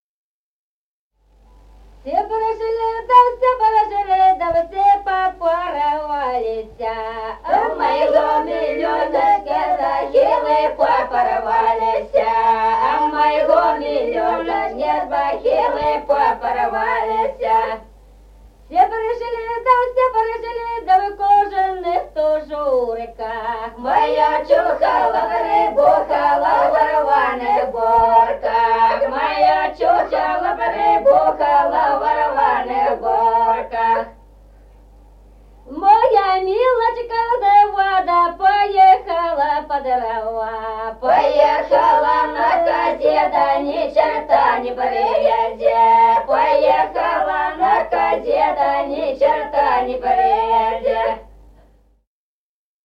| filedescription = Все пришли (припевки) И 0064-05
Песни села Остроглядово.